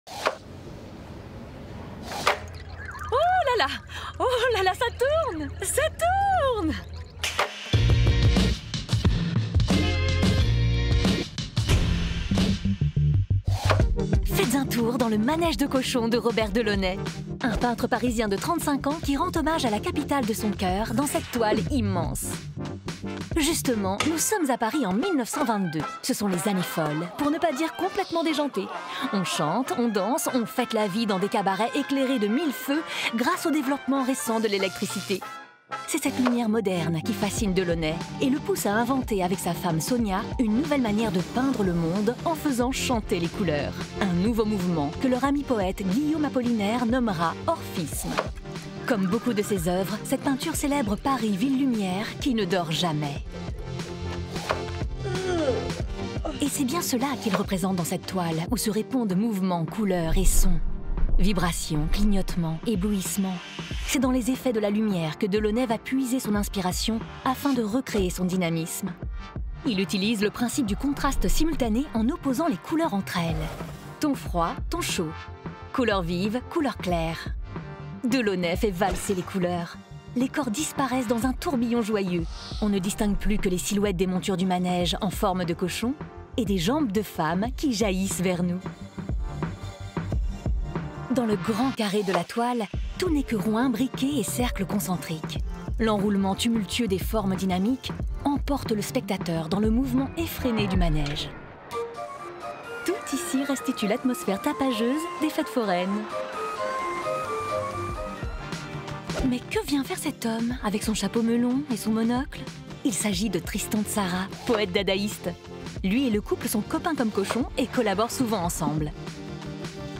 Voix off « Quèsaco » TV5MONDE & Le Centre Pompidou « Le manège de cochons » de Robert Delaunay